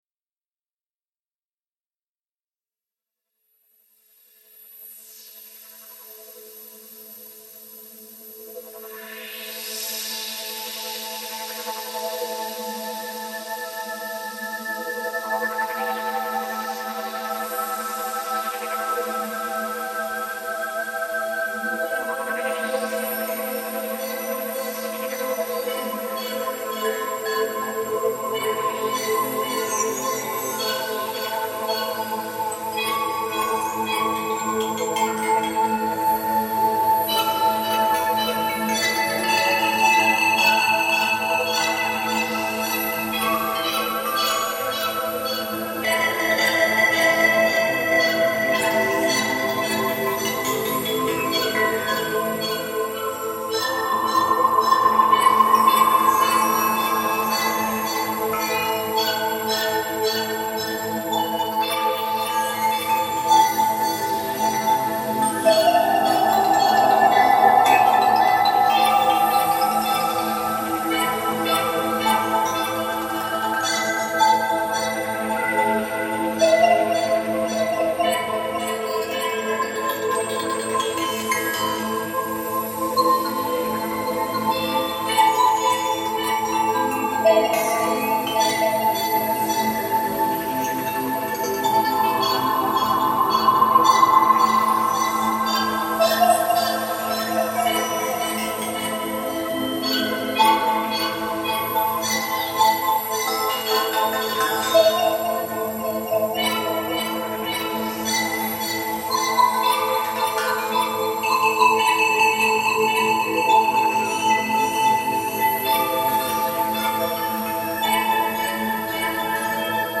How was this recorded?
Lerici forest reimagined